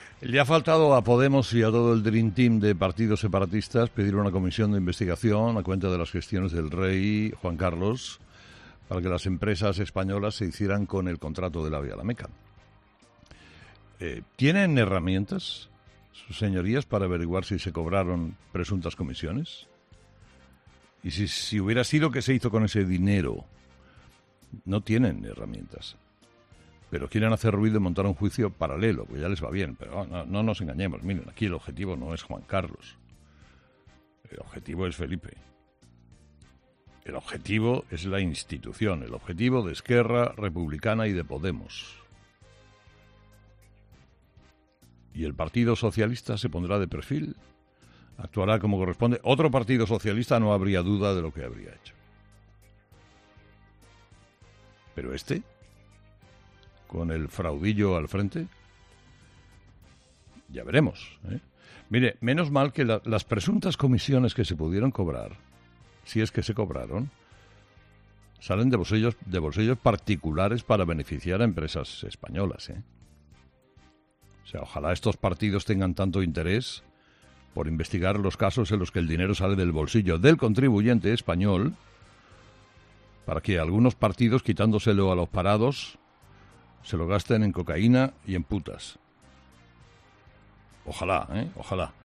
Carlos Herrera, presentador y director de 'Herrera en COPE', ha comenzado el programa de este miércoles analizando los próximos pasos del Gobierno para intentar alcanzar la "nueva normalidad" o como se ha conocido en los últimos días "normalidad provisional".